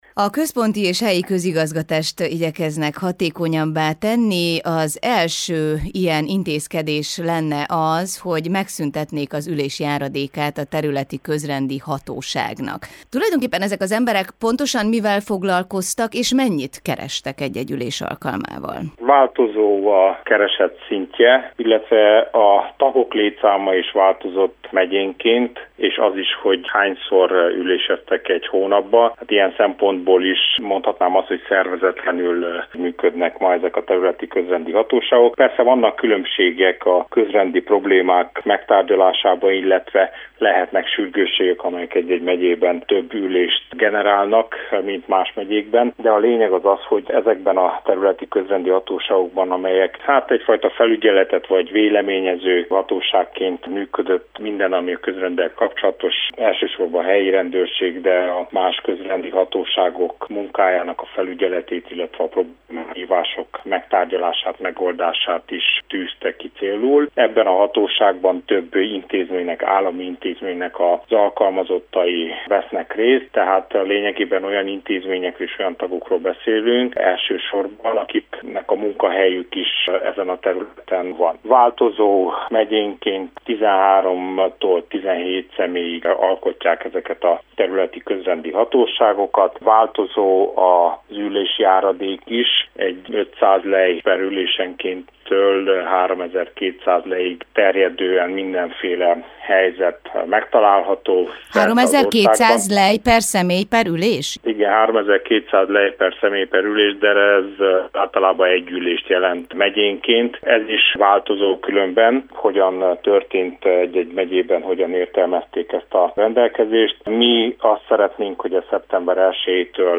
Cseke Attila fejlesztési és közigazgatási minisztert kérdeztük az első bejelentett intézkedésről.